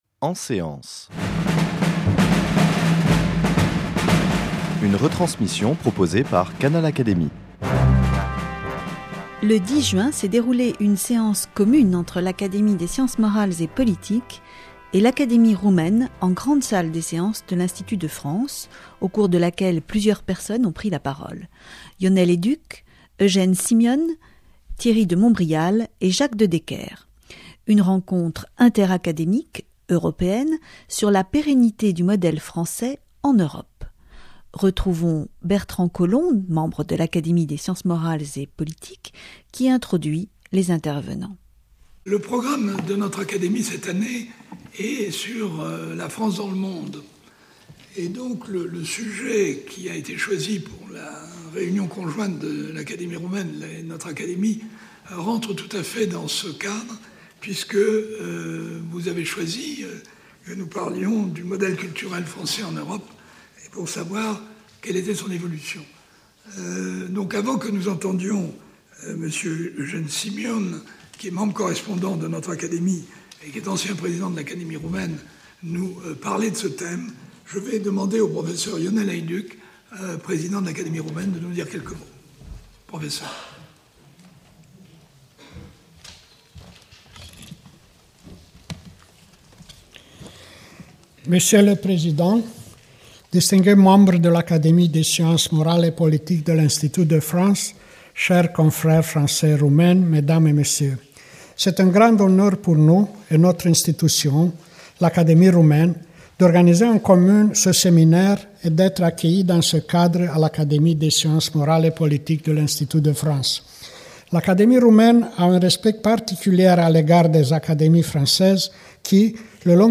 Le 10 juin s’est déroulée une séance commune entre l’Académie des sciences morales et politiques et l’Académie Roumaine, en Grande salle des séances de l’Institut de France au cours de laquelle plusieurs personnes ont pris la parole : Ionel Haiduc, Eugen Simion, Thierry de Montbrial et Jacques De Decker. Une rencontre interacadémique européenne sur la pérennité du modèle français en Europe.